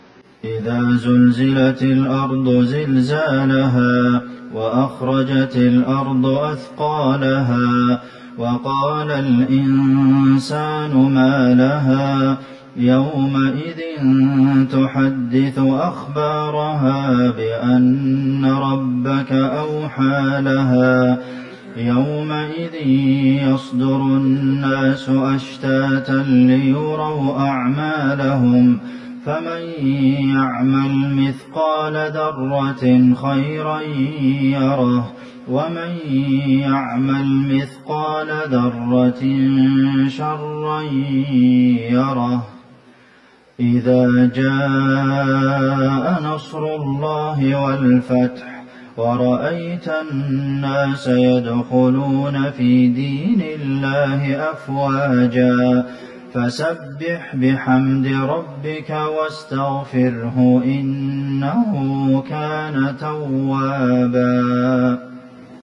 صلاة المغرب ١٤٤١/١/٩ لِـ سورتي الزلزلة والنصر | Maghrib Prayer from Surat Az-Zalzalah and An-Nasser > 1441 🕌 > الفروض - تلاوات الحرمين